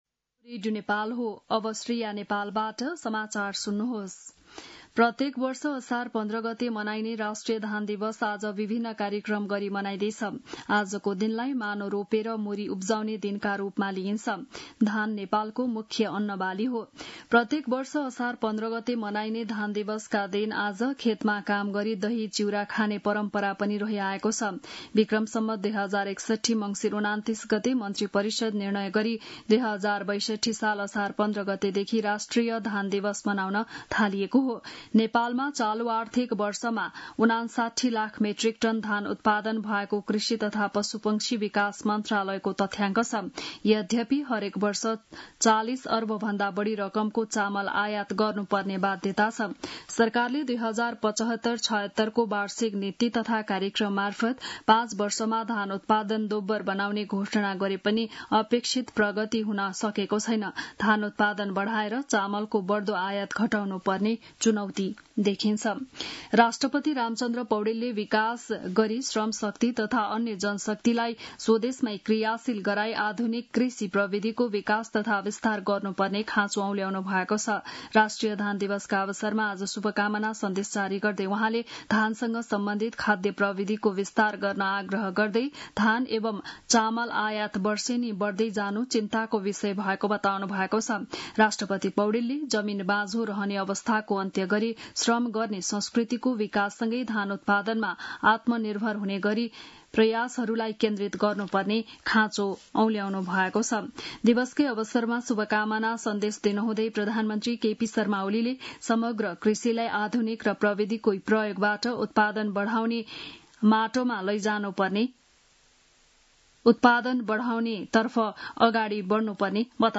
बिहान ११ बजेको नेपाली समाचार : १५ असार , २०८२